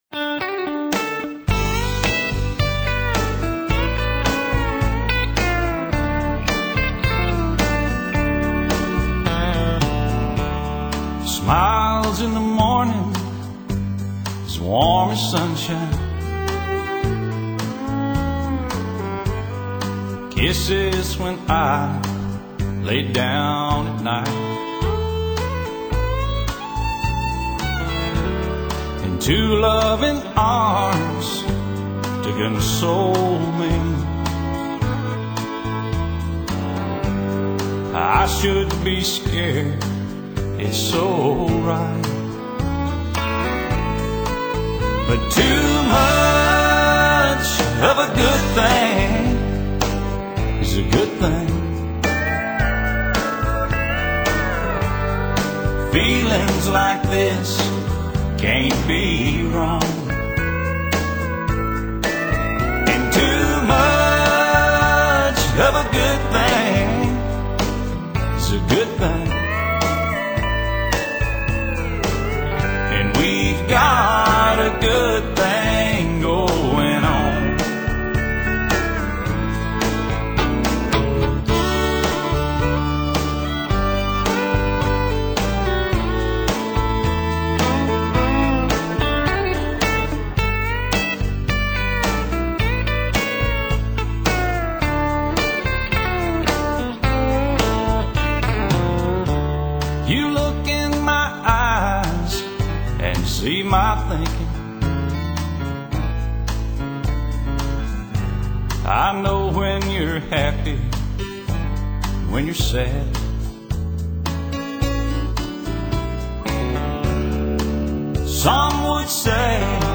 乡村音乐不像纯古典音乐離自己很遥远；也不像摇滚、重金属音乐那样嘈杂。